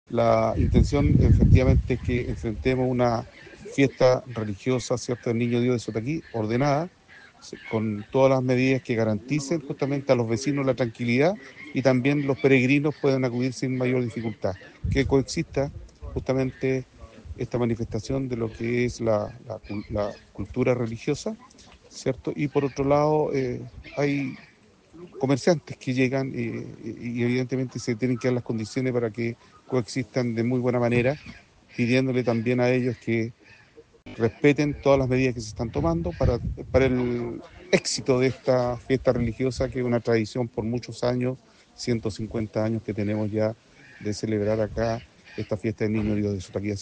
El Delegado Presidencial de Limarí, Eduardo Alcayaga Cortés, indicó
Eduardo-Alcayaga-Cortes-DPP-Limari.mp3